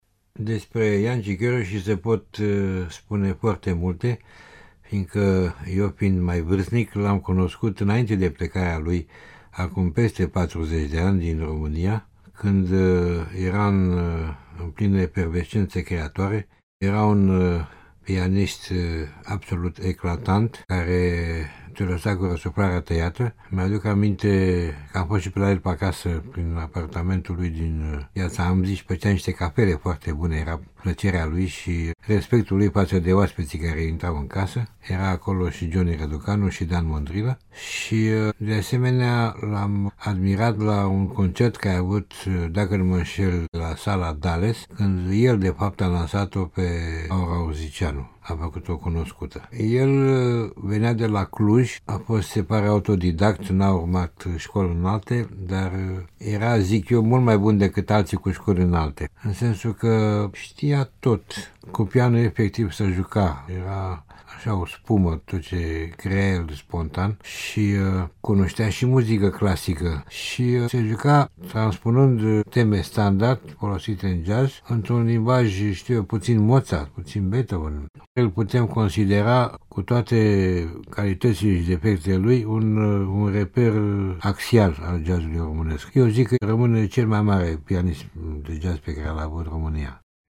Despre locul pe care il ocupa Jancy Korossy in jazzul romanesc mi-a vorbit si realizatorul de emisiuni de jazz Florian Lungu, binecunoscut si ca prezentator de concerte, un mare sustinator al muzicienilor romani de jazz: